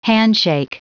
Prononciation du mot handshake en anglais (fichier audio)
Prononciation du mot : handshake